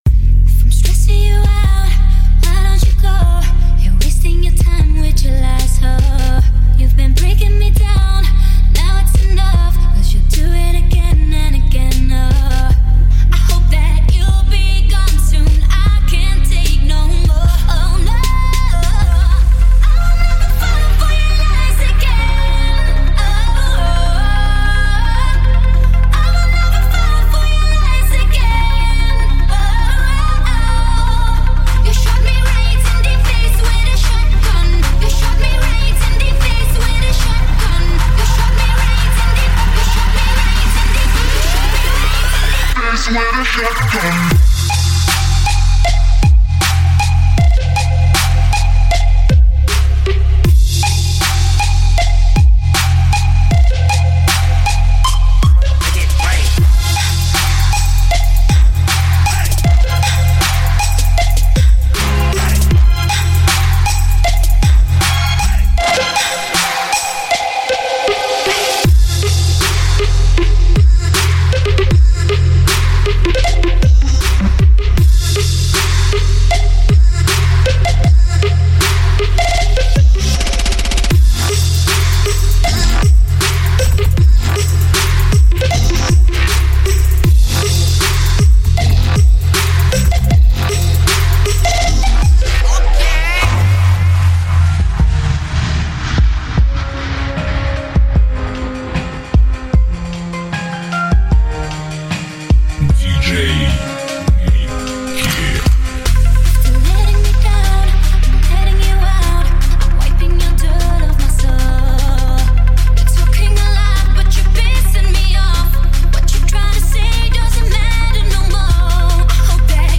басы в авто